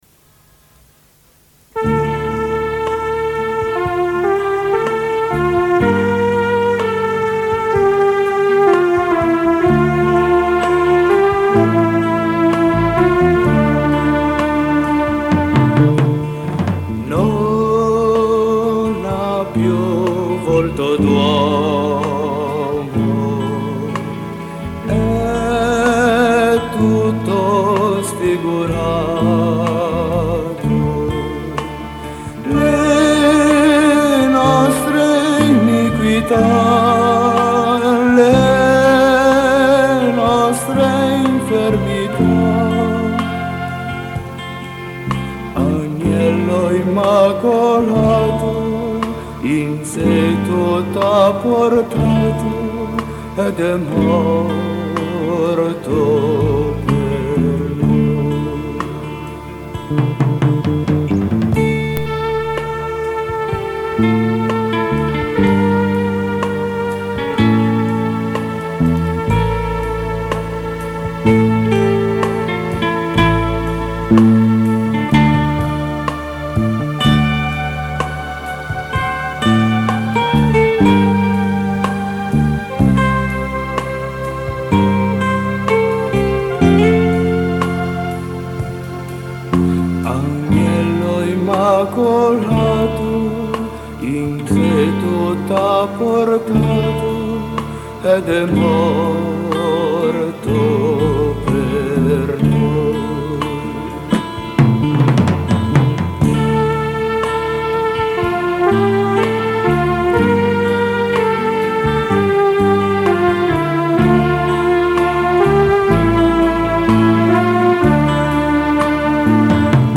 Canto per la Decina di Rosario e Parola di Dio: Non ha più volto d’uomo